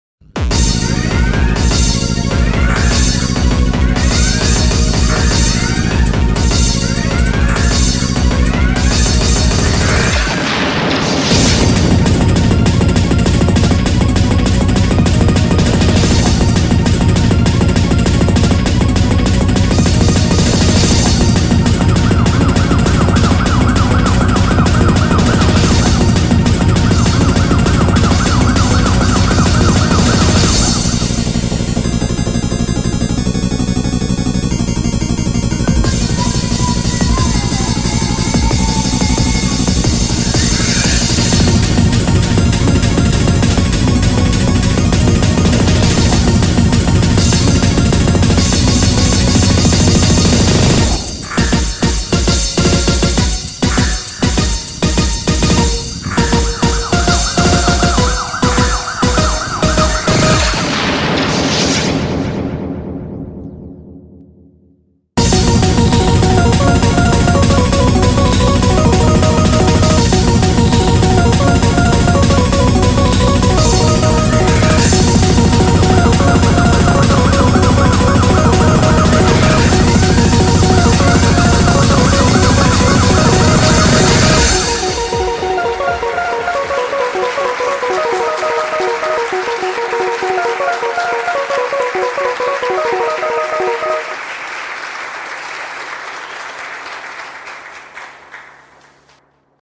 BPM100-400
Audio QualityPerfect (High Quality)